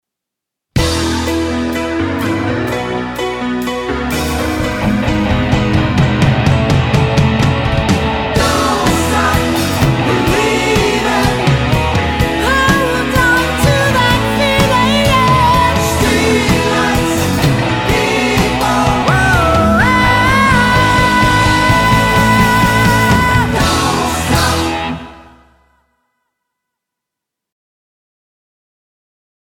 I track drums from start to finish without fixes.
TRIBUTE BAND